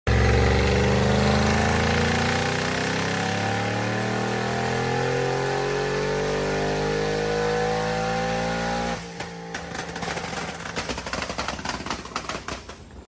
700+ Whp!! 2017 Audi RS7 Sound Effects Free Download